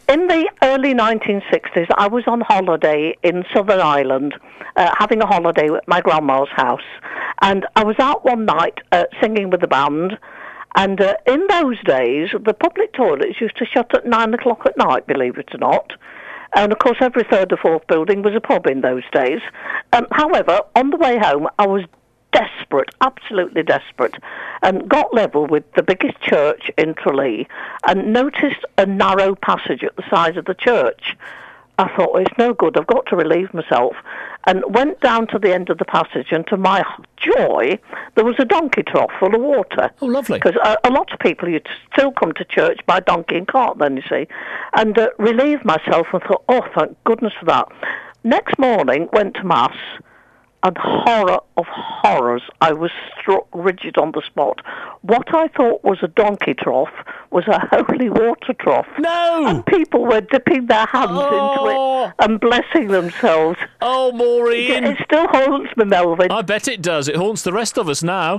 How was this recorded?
A wonderful story about having a wee - as broadcast just now